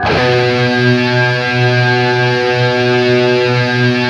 LEAD B 1 LP.wav